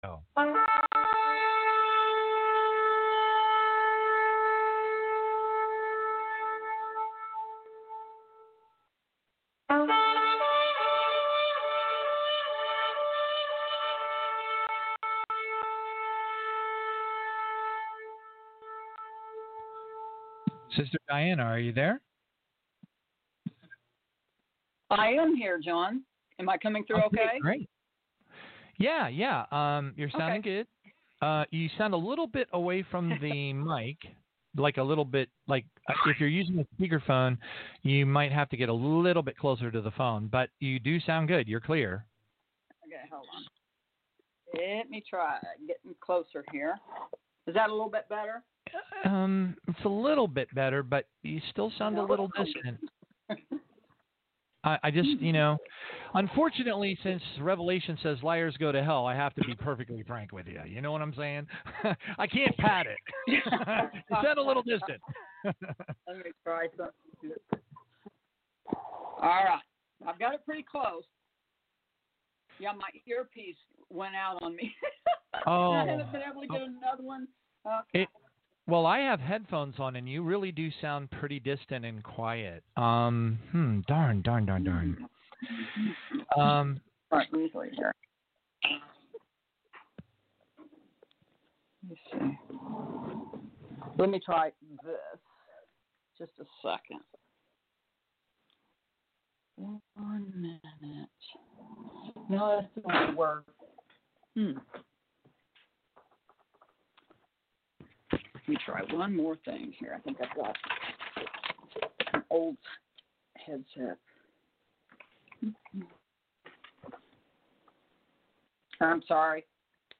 Tribulation-Now Interview (The Blood Moons Revisited – God’s Message to His People)
This interview was given February 21, 2020, on Tribulation-Now Radio. I talk about the Blood Moons of 2014-15, and the staggering message our God was giving His people about the time we’re in NOW!